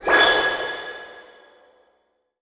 magic_light_bubble_01.wav